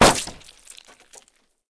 splat.ogg